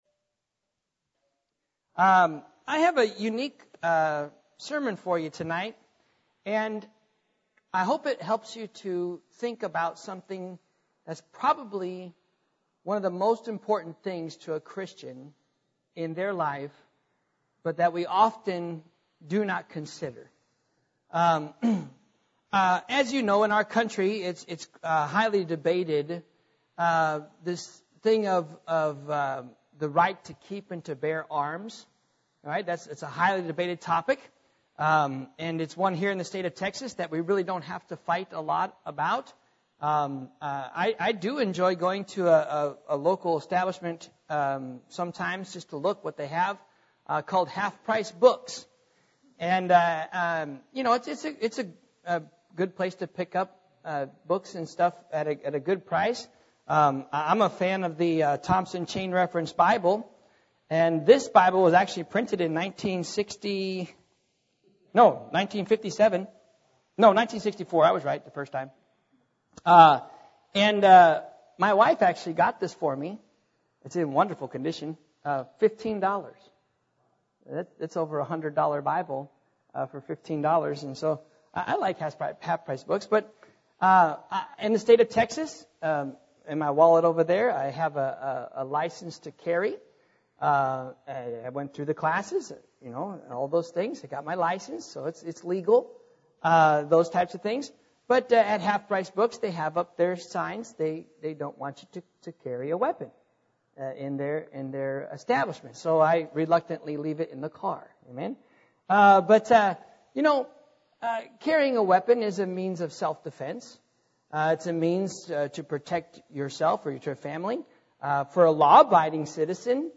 Sermon Archive - 2020